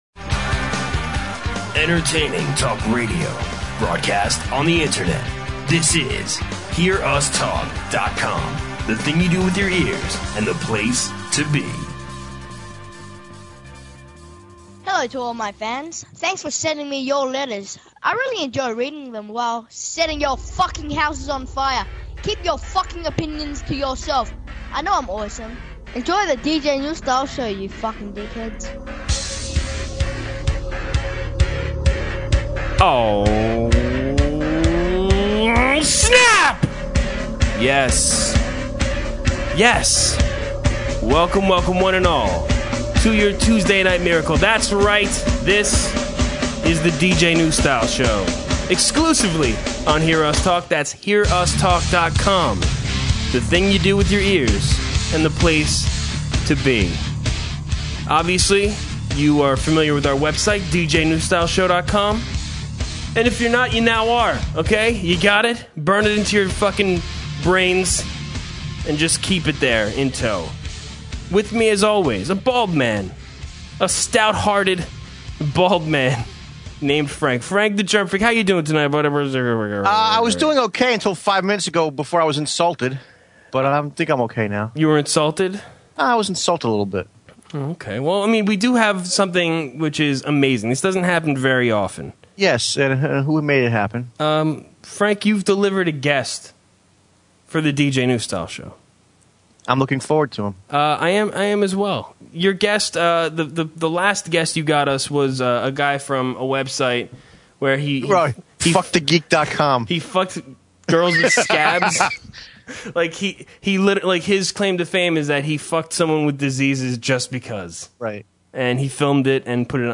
It’s difficult to describe this interview.